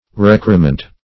Recrement \Rec"re*ment\ (r?k"r?*ment), n. [L. recrementum; pref.